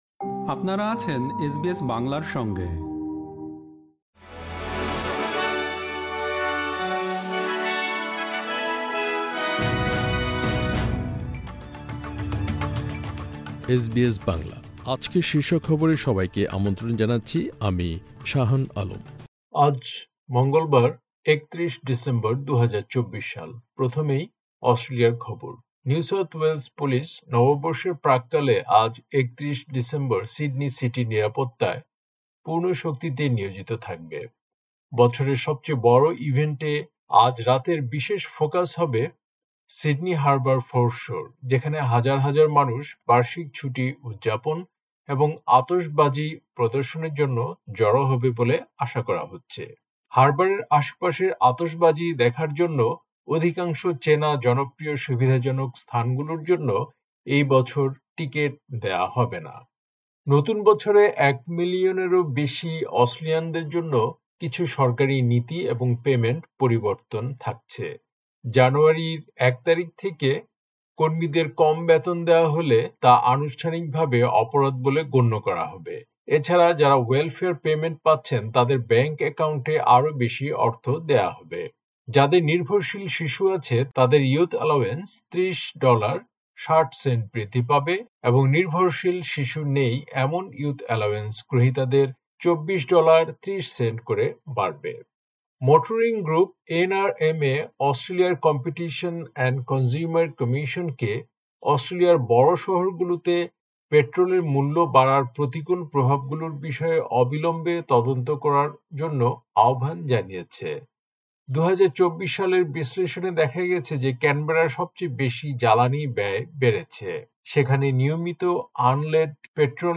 এসবিএস বাংলা শীর্ষ খবর: ৩১ ডিসেম্বর, ২০২৪